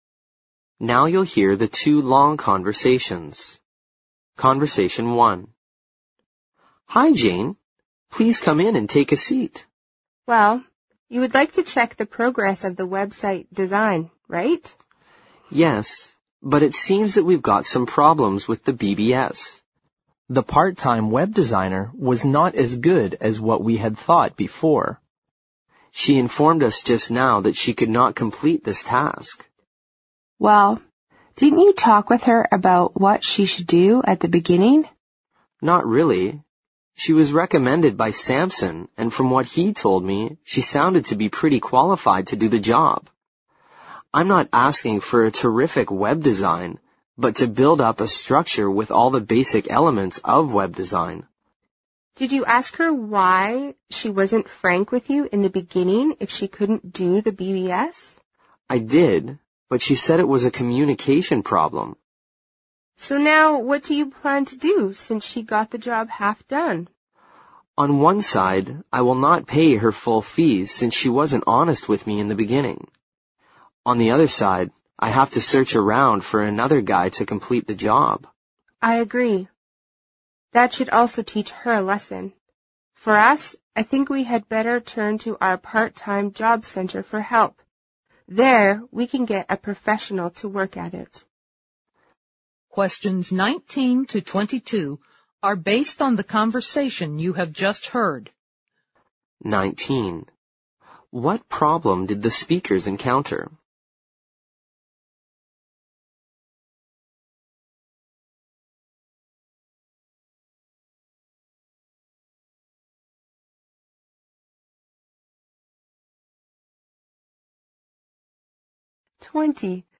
Now you'll hear the two long conversations.
Conversation One